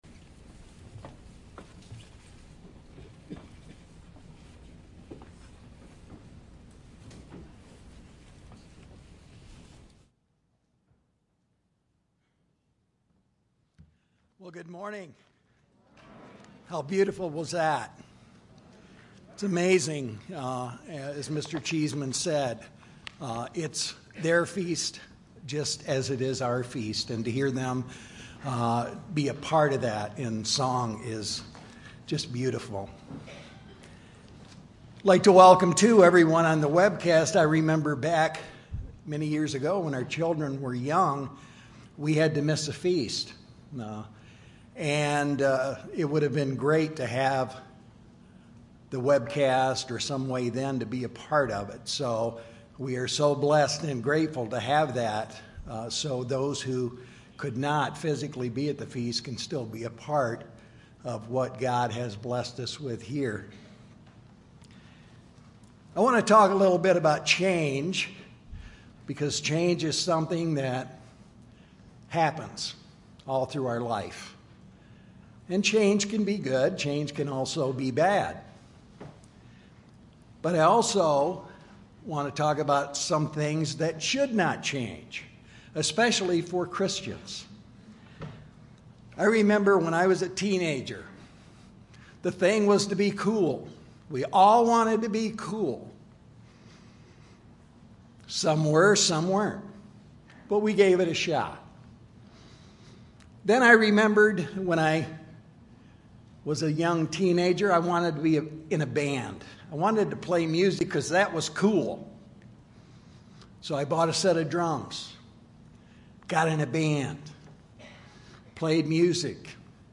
This sermon was given at the Lake Junaluska, North Carolina 2017 Feast site.